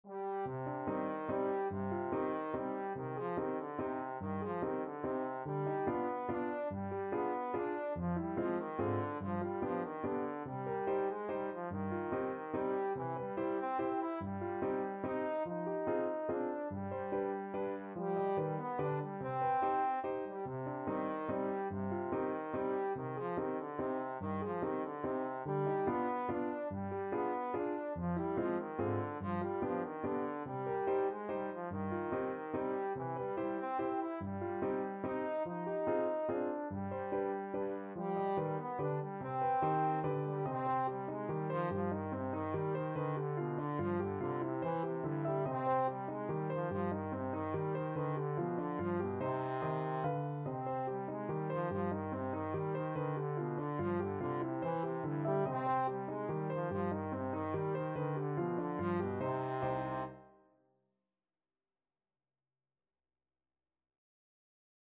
Trombone
C minor (Sounding Pitch) (View more C minor Music for Trombone )
Molto Allegro = c.144 (View more music marked Allegro)
3/4 (View more 3/4 Music)
Traditional (View more Traditional Trombone Music)
Finnish